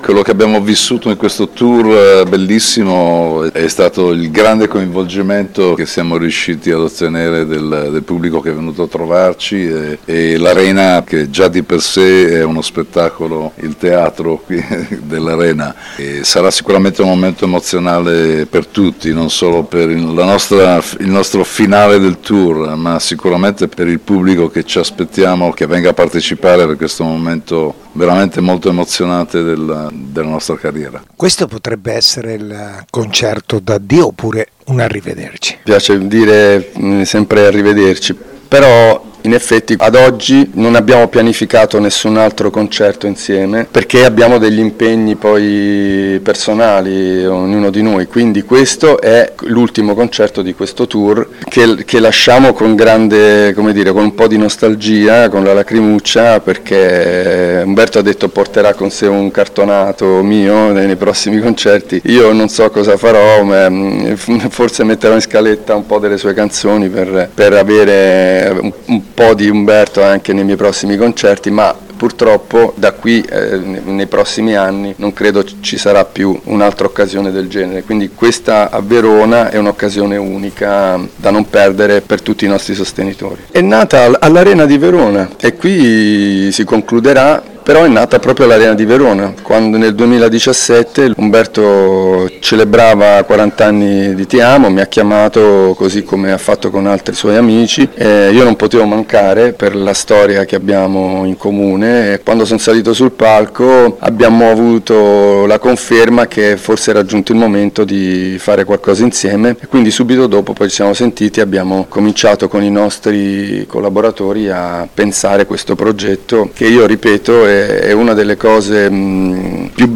Raf e Tozzi concluderanno il loro tour, la cui idea è nata sul palco del’Arena di Verona nel corso del concerto-evento di Tozzi “40 Anni Che Ti Amo”, il 25 settembre proprio nell’Anfiteatro Veronese, come hanno raccontato al nostro microfono:
Intervista-a-Raff-e-Umberto-Tozzi-alla-presentazione-della-serata-del-25-settembre-allArena-di-Verona.mp3